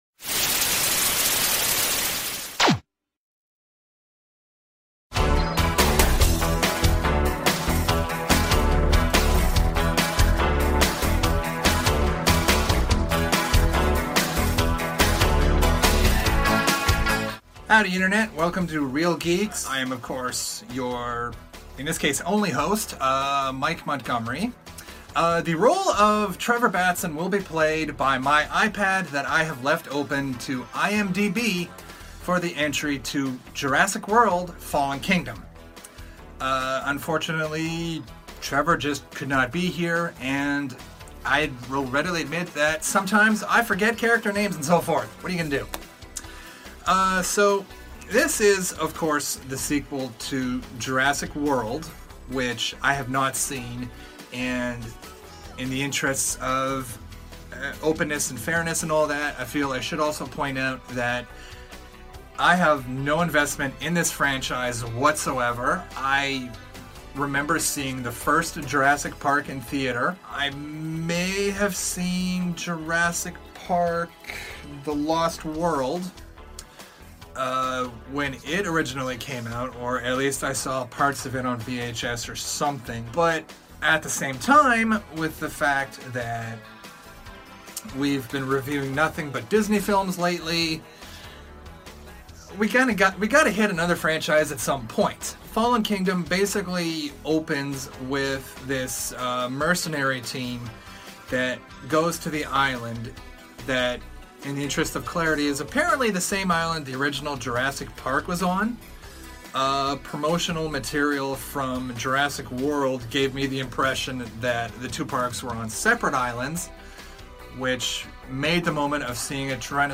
Originally recorded in Halifax, NS, Canada